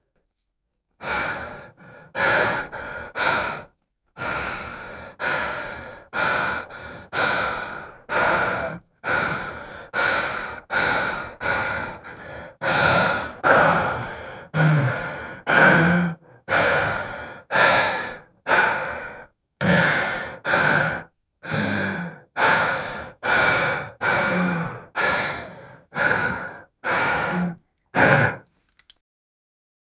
young man having struggle breathing sensually
young-man-having-struggle-o2qx6vgw.wav